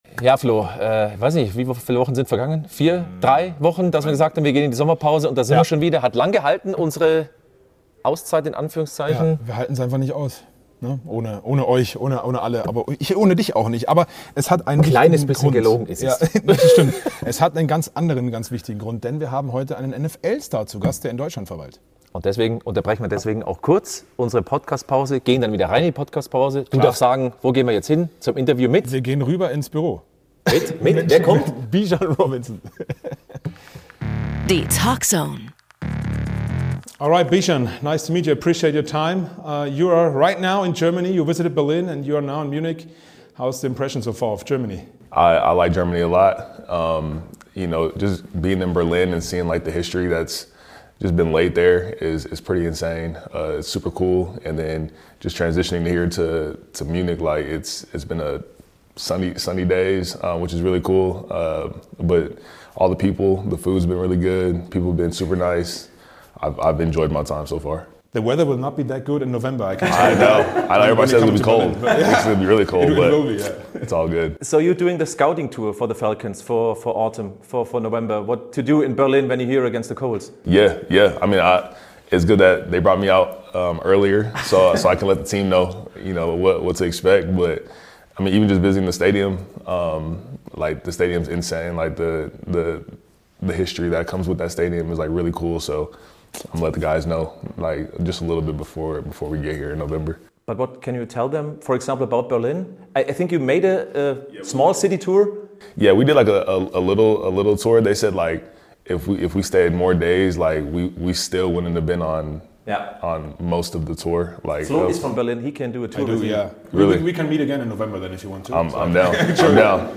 Wir haben uns mit dem Running Back zum Interview getroffen und richtig spannende Einblicke bekommen. Wie schätzt er die (enttäuschende) abgelaufene Falcons-Saison ein?